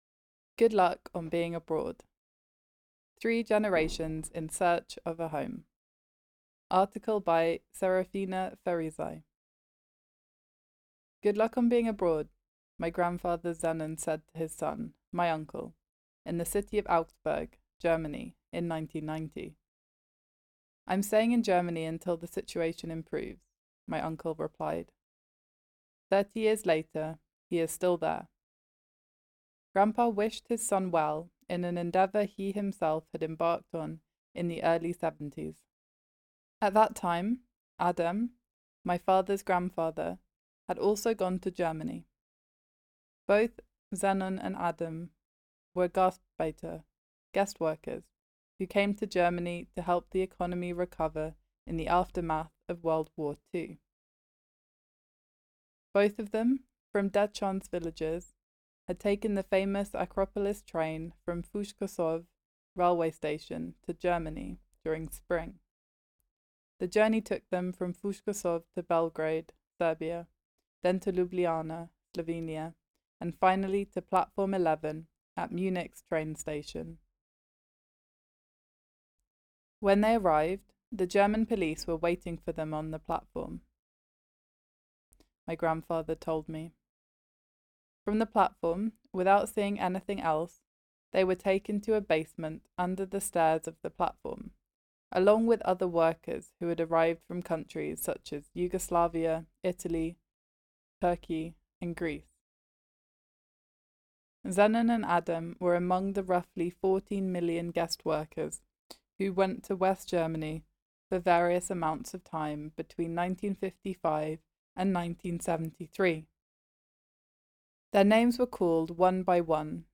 Below is a read-aloud version of the entire article.
Germany-Diaspora-Narrated-in-English-Final.mp3